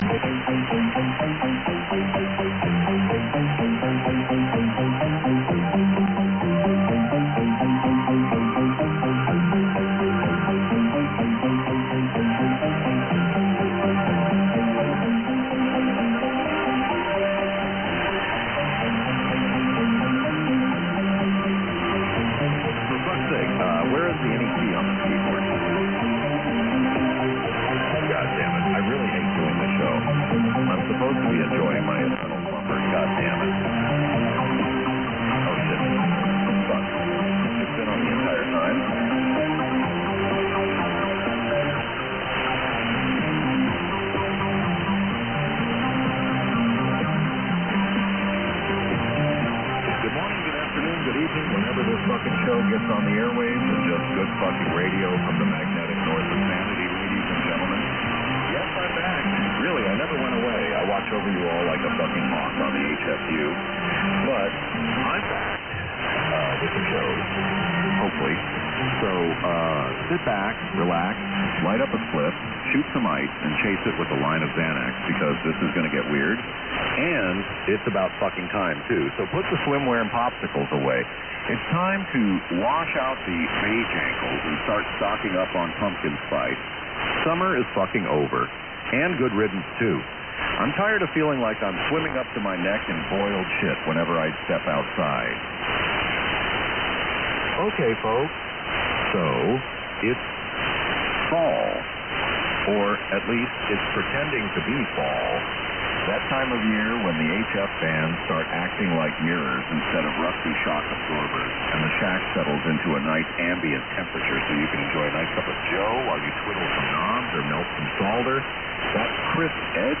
A very entertaining pirate radio broadcaster usually on 6950 USB, this shortwave pirate featured a very realistic AI generated voice of former (now deceased) late night AM radio broadcaster, Art Bell. Themes were mainly comedic and had lots of HFU referenced material.